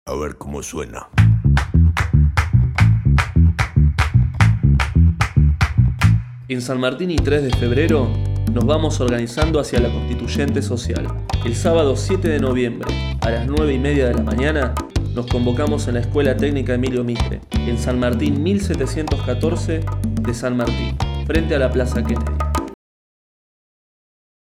Spot_corto_Constituyente_san_martin.mp3